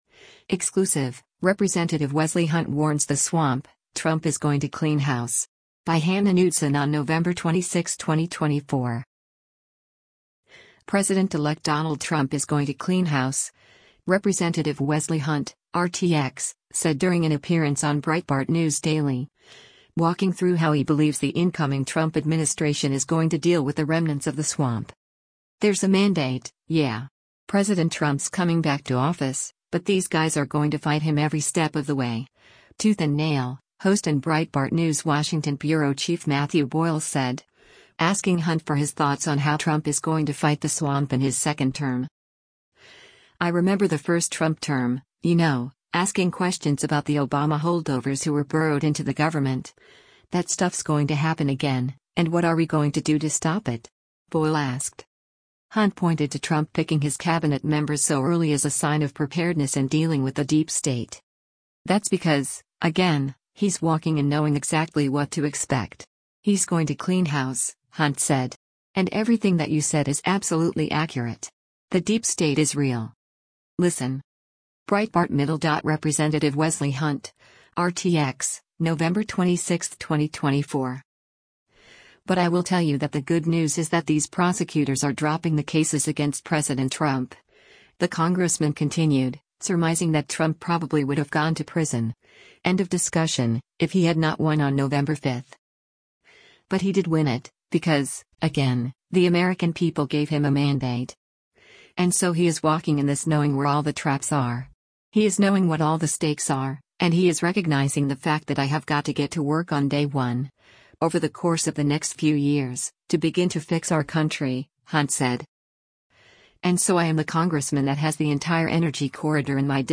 President-elect Donald Trump is “going to clean house,” Rep. Wesley Hunt (R-TX) said during an appearance on Breitbart News Daily, walking through how he believes the incoming Trump administration is going to deal with the remnants of the swamp.
Breitbart News Daily airs on SiriusXM Patriot 125 from 6:00 a.m. to 9:00 a.m. Eastern.